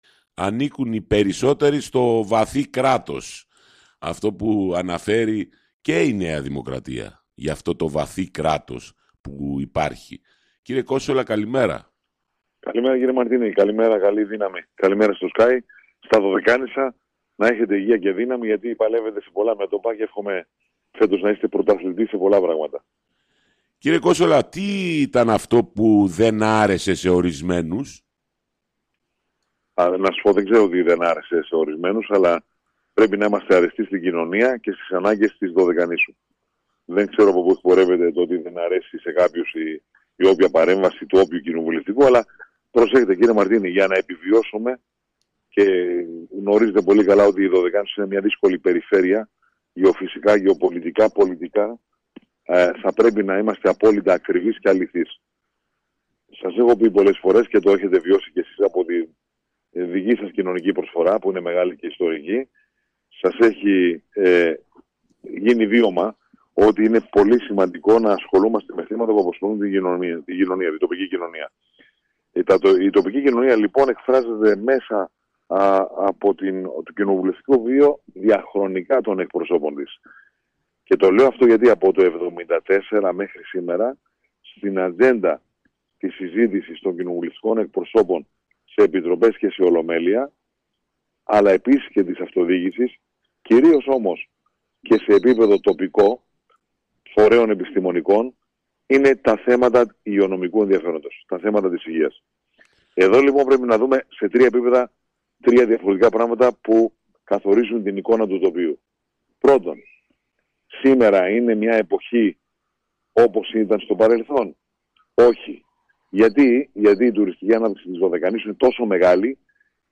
Στην πολιτική επικαιρότητα αλλά και την προκήρυξη 14 θέσεων για την κάλυψη κενών  θέσεων στα νοσοκομεία και τα κέντρα Υγείας της Δωδεκανήσου αναφέρθηκε μιλώντας σήμερα στον Sky o βουλευτής της ΝΔ κ. Μάνος Κόνσολας.